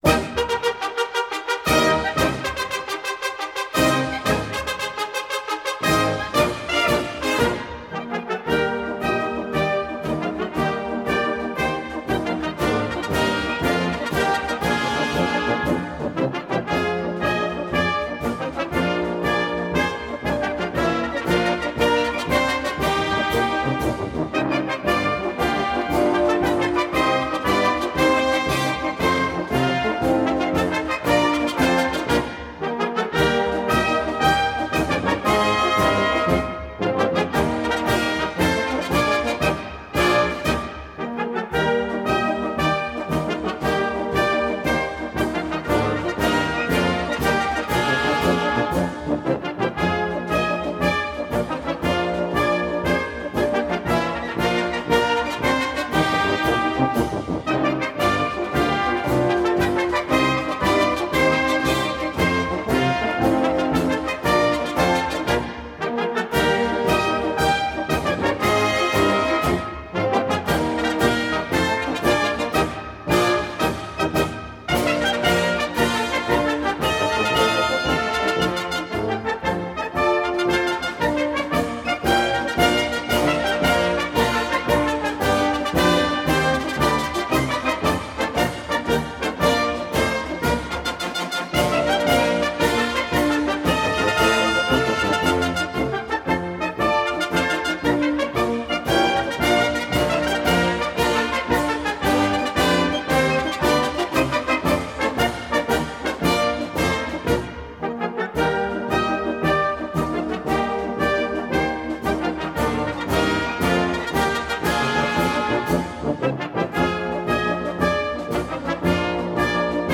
Marsch für großes Blasorchester…